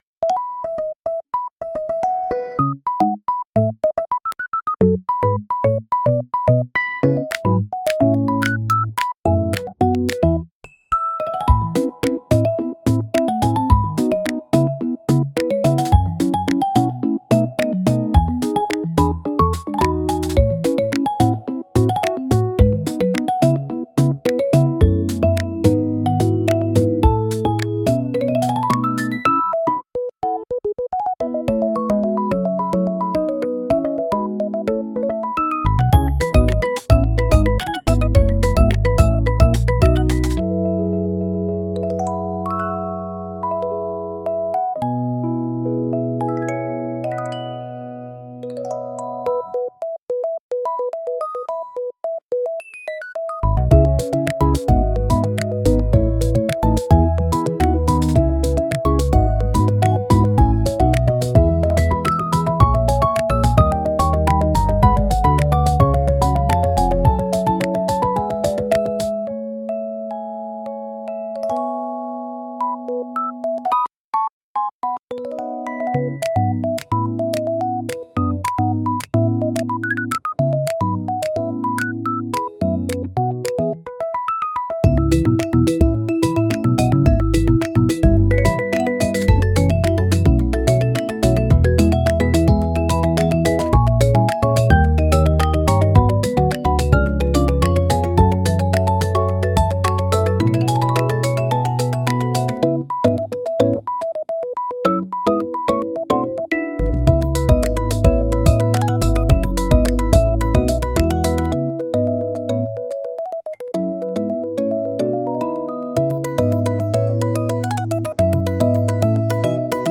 SUNO AI を使用して制作しています
リズミカルなトイBGM